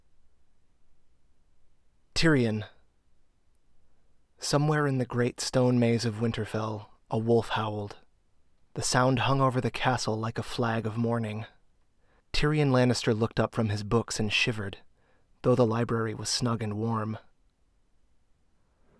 It even passes noise by the fuzzy rule of quieter than -65dB.